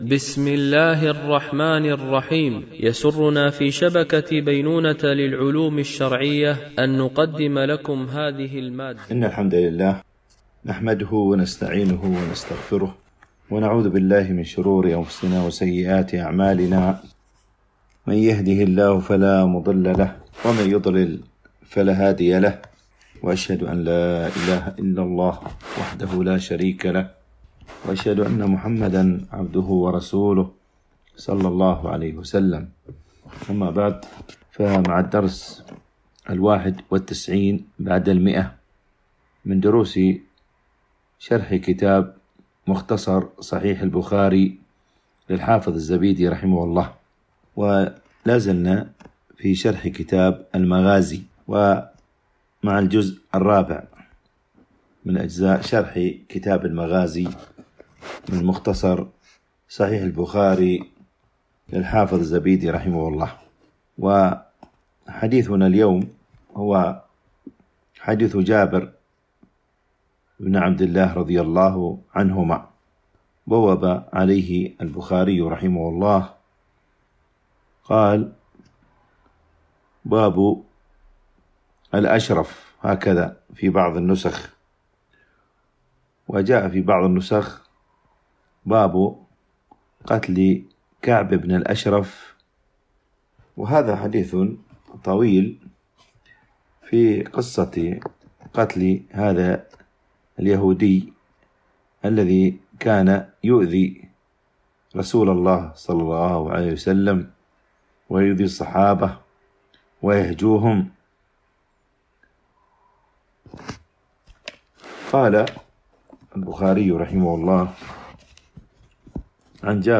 شرح مختصر صحيح البخاري ـ الدرس 191 ( كتاب المغازي ـ الجزء الرابع - الحديث 1616 - 1617 )